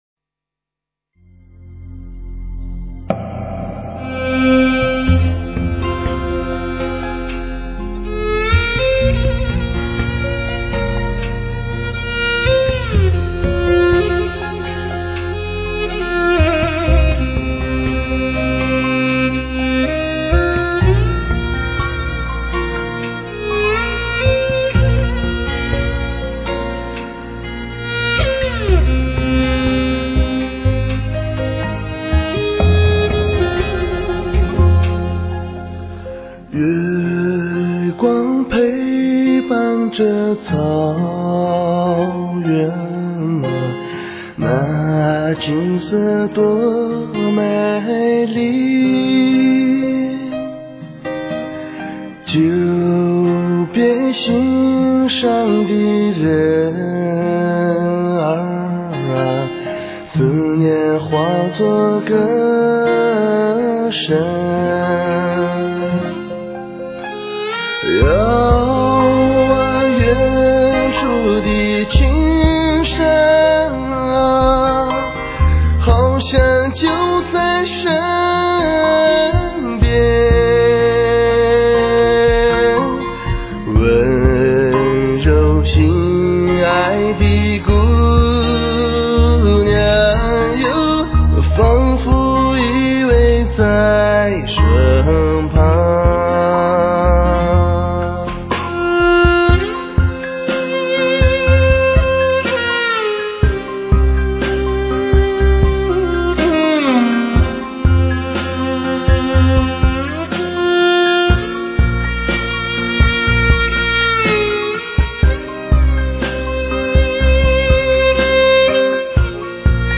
一把让我们期待已久的靓声，唱颂出草原上四季的欢乐和哀愁。
超凡的录音，配合本色的演唱，让你们一同感受草原四季的欢乐与哀愁！